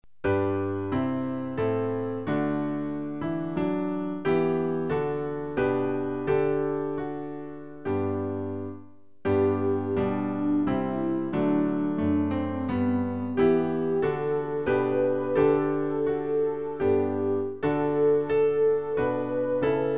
Klavier-Playback zur Begleitung der Gemeinde MP3 Download